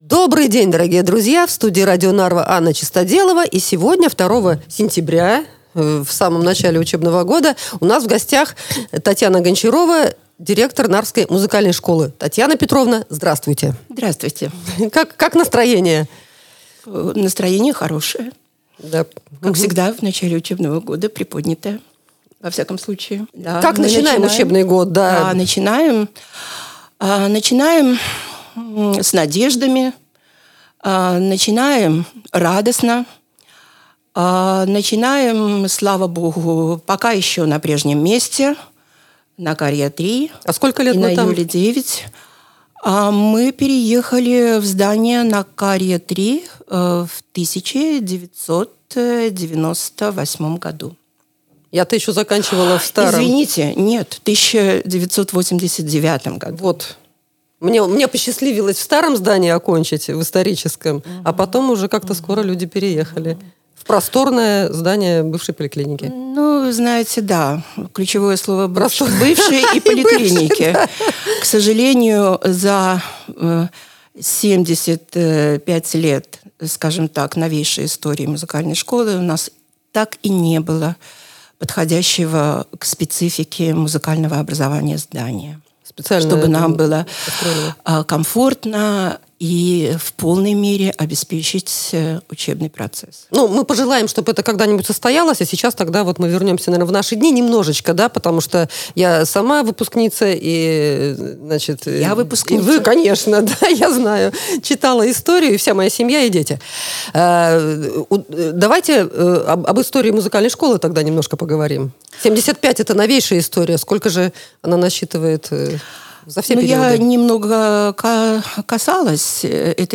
в студии Radio Narva